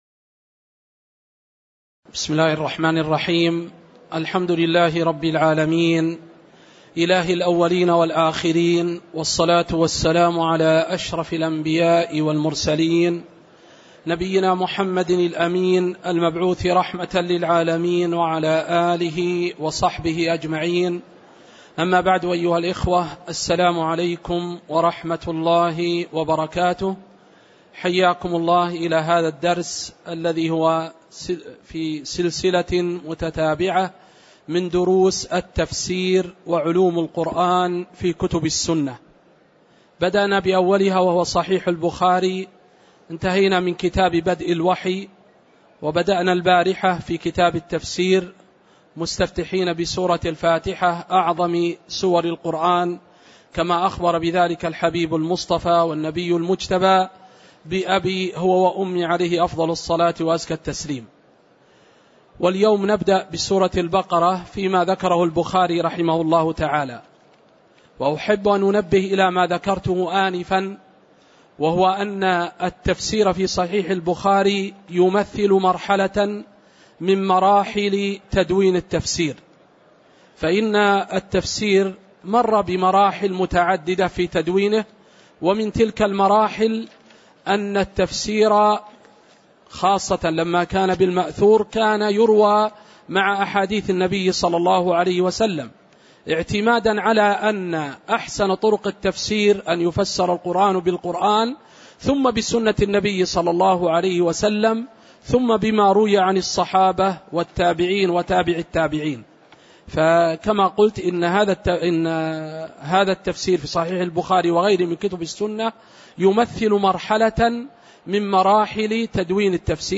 تاريخ النشر ٣ ربيع الثاني ١٤٣٩ هـ المكان: المسجد النبوي الشيخ